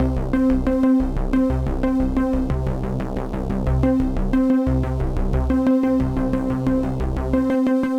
Index of /musicradar/dystopian-drone-samples/Droney Arps/90bpm
DD_DroneyArp3_90-C.wav